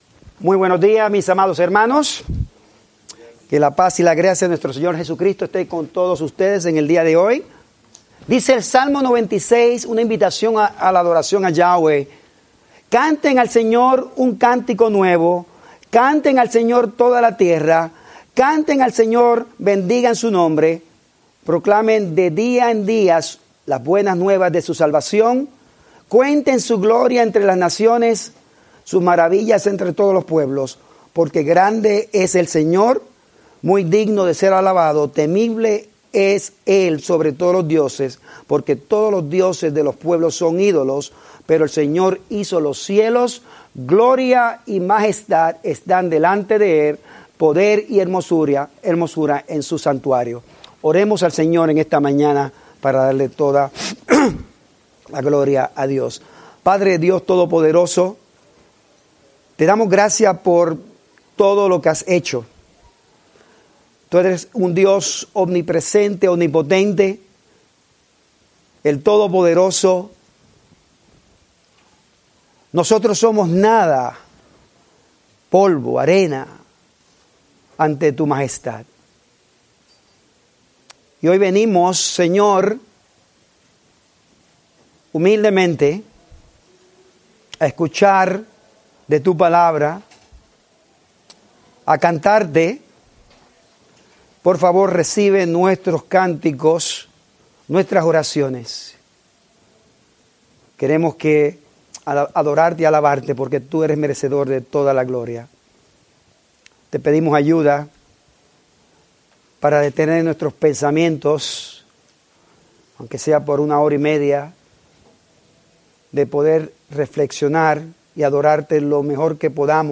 Spanish Bible Study